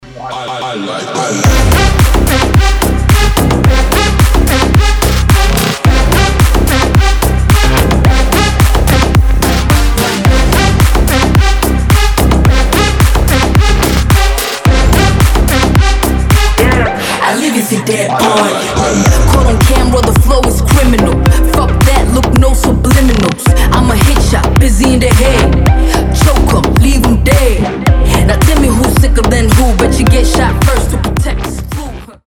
• Качество: 320, Stereo
EDM
мощные басы
electro house
G-House